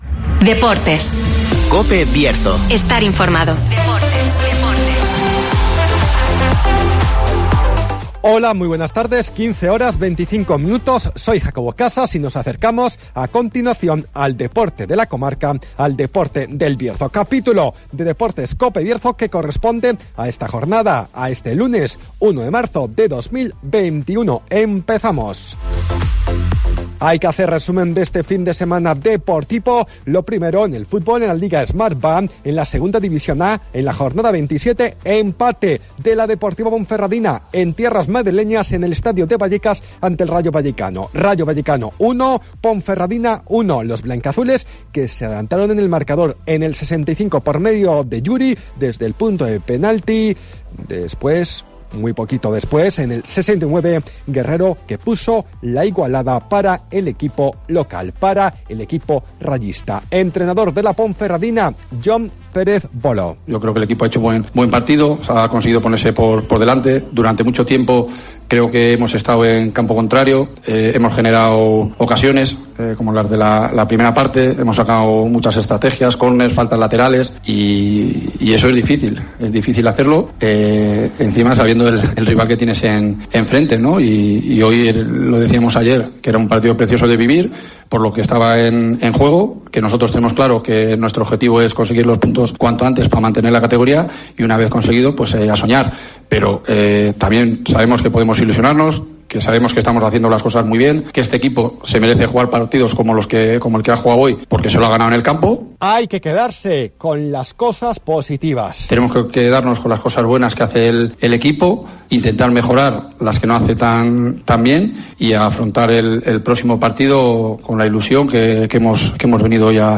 DEPORTES